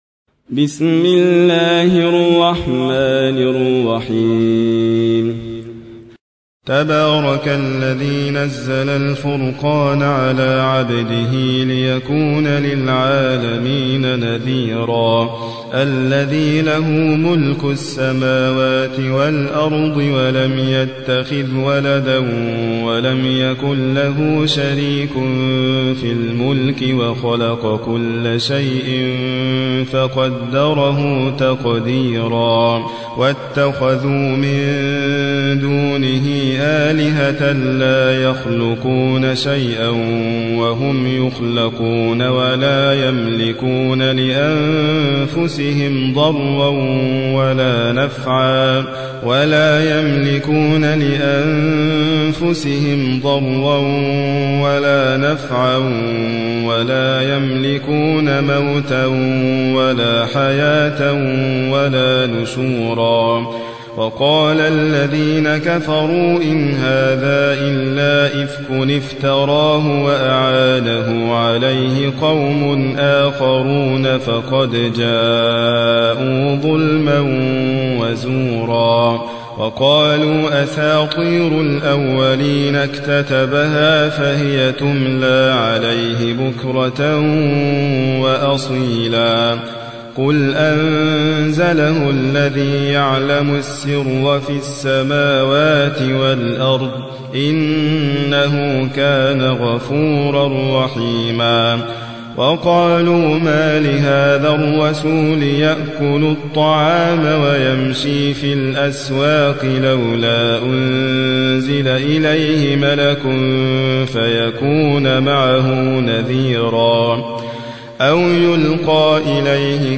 25. سورة الفرقان / القارئ